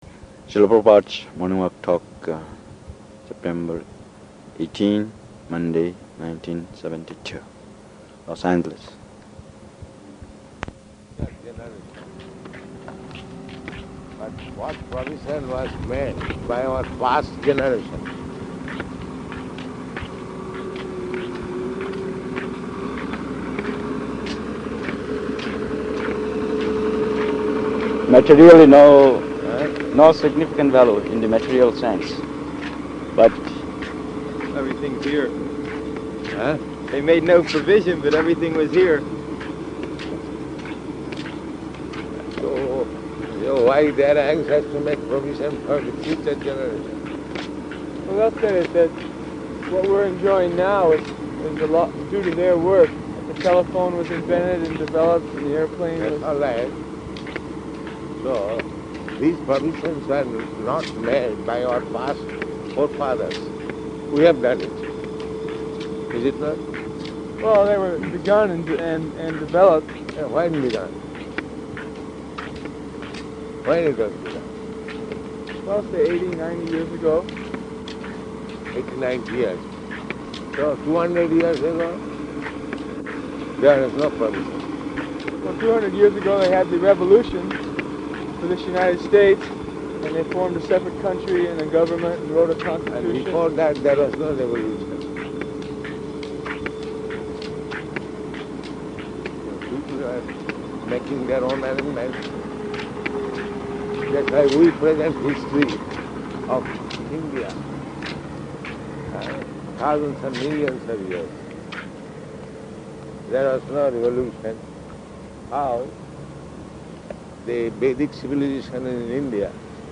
-- Type: Walk Dated: September 18th 1972 Location: Los Angeles Audio file